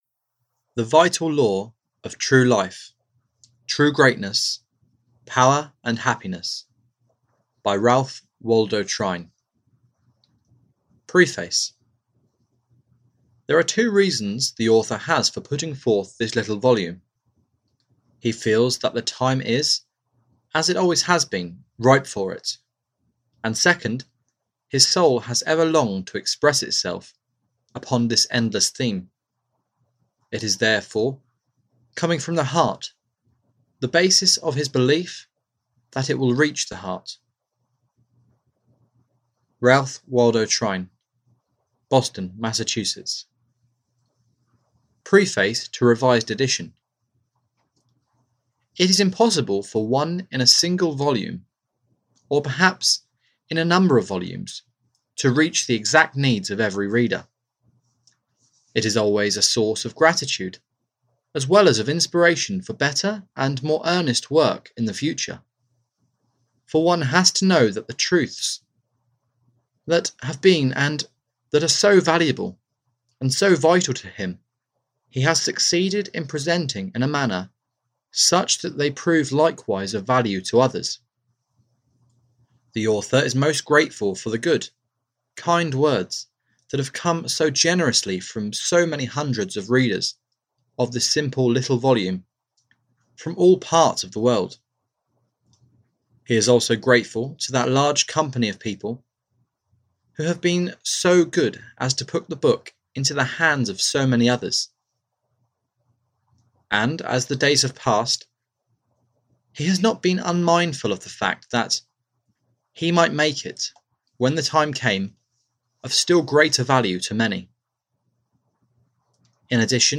Audio knihaThe Vital Law Of Life: True Greatness, Power and Happiness (EN)
Ukázka z knihy